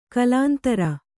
♪ kalāntara